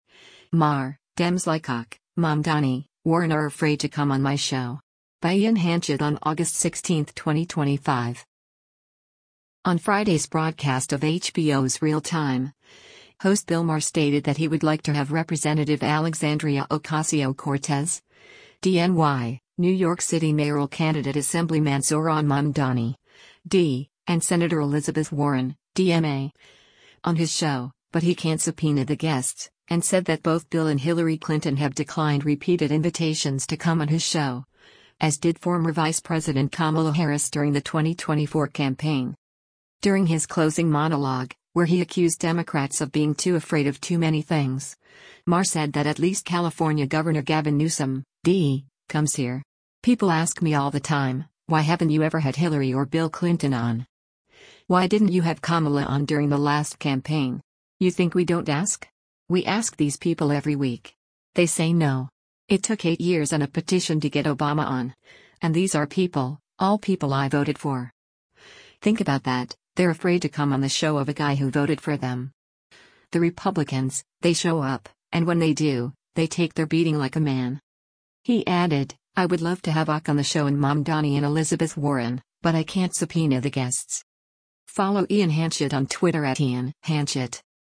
During his closing monologue, where he accused Democrats of being too afraid of too many things, Maher said that at least California Gov. Gavin Newsom (D) “comes here.